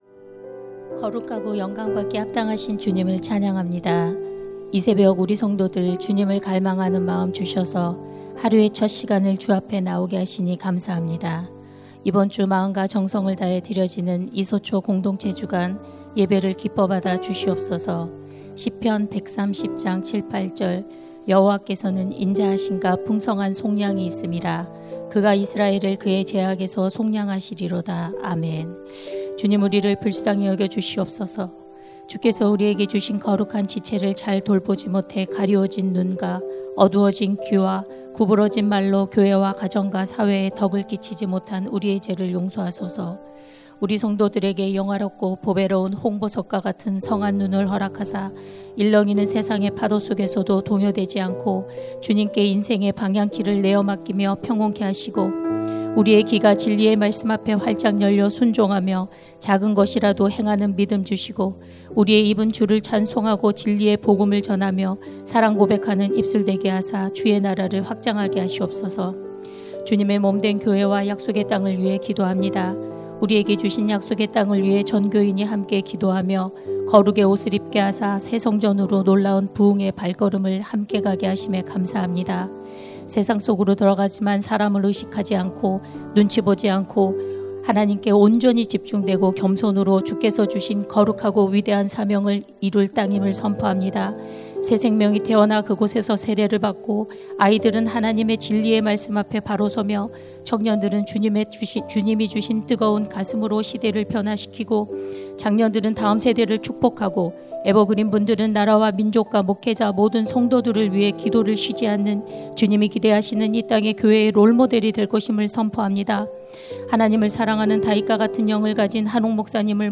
2026-03-19 공동체 주관 새벽기도회
> 설교